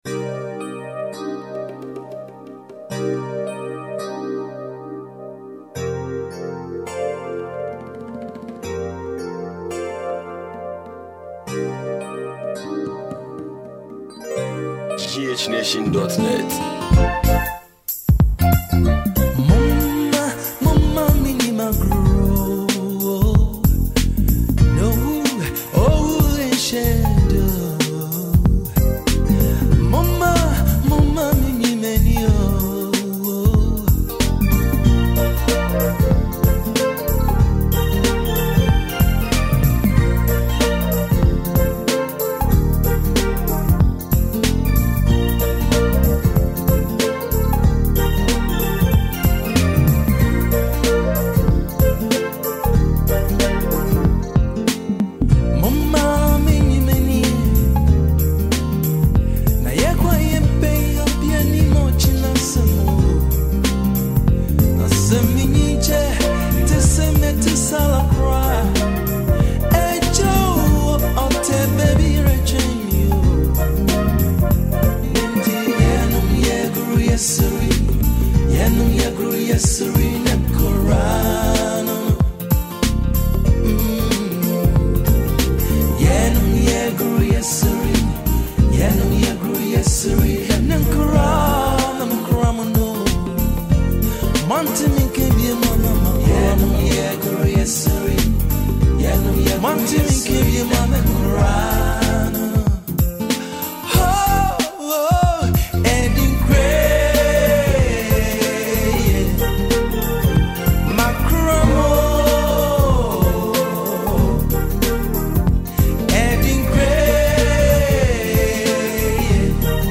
traditional song